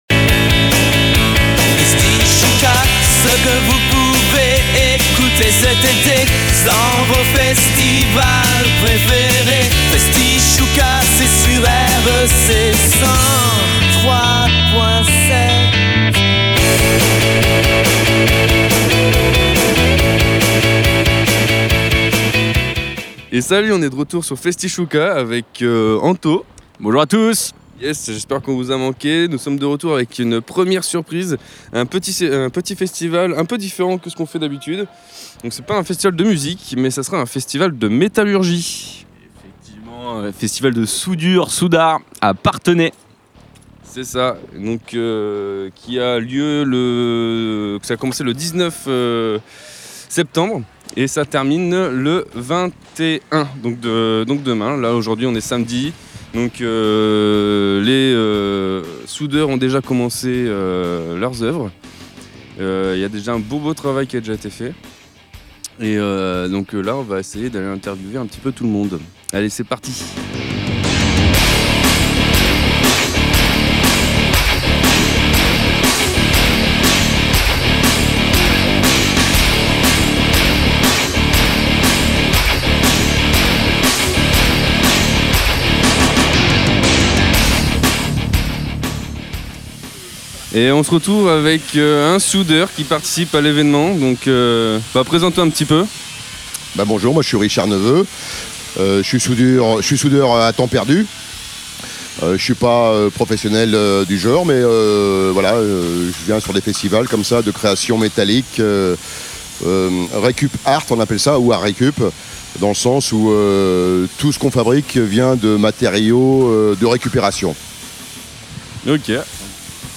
ITW des orgas et des participant du festival de métallurgie « Soud’Art »